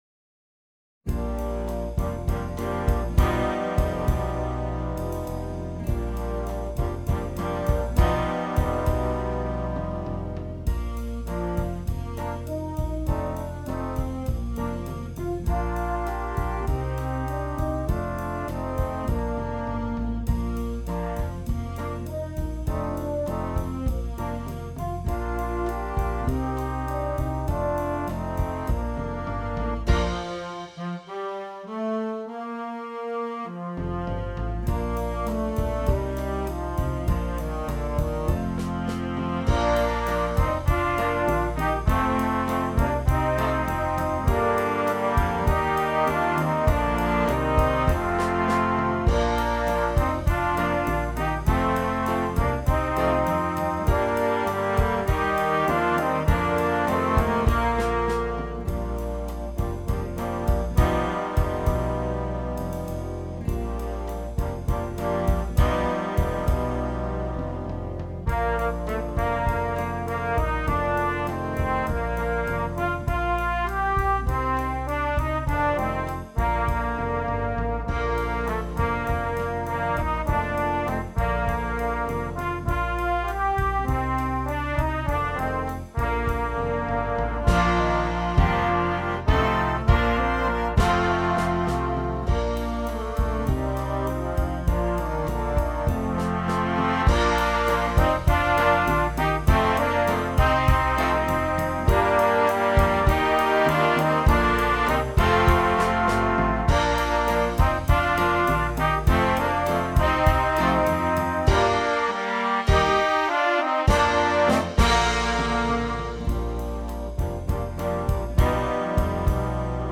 Jazz Band
Traditional